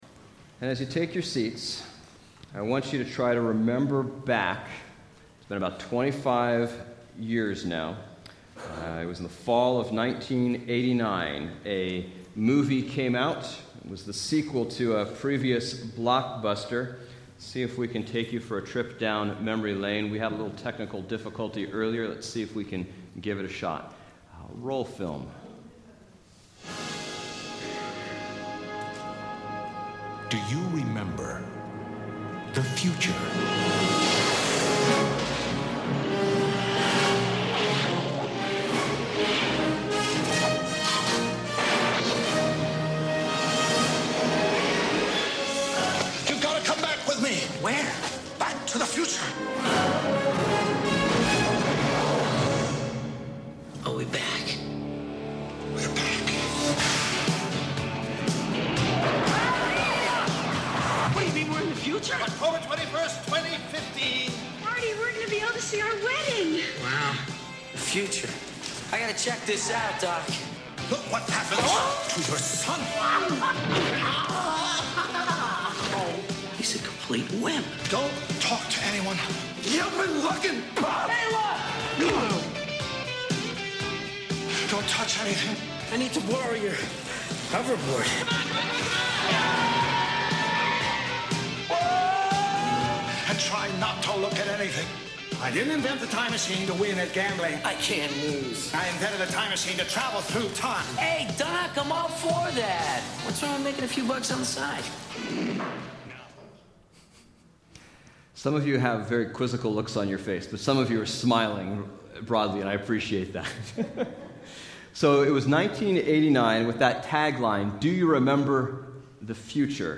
Bible Text: Luke 12:1-13:9 | Preacher